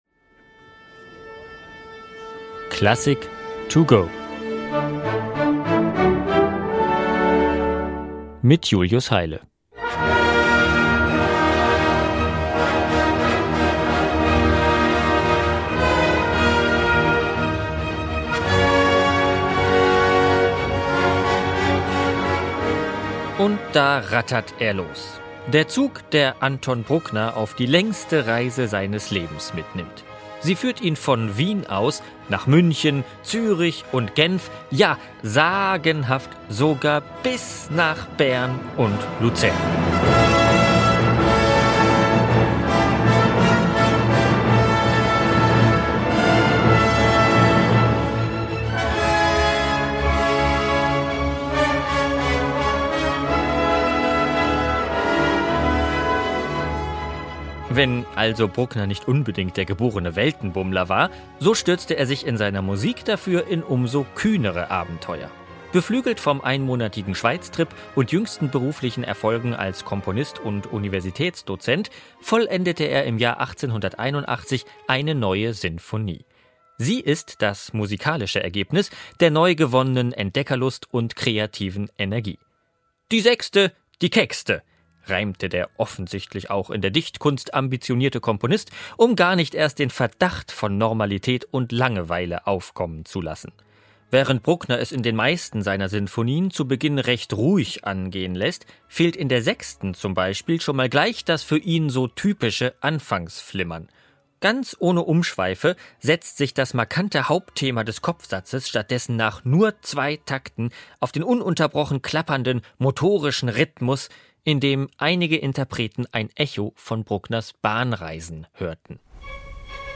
Kurzeinführung.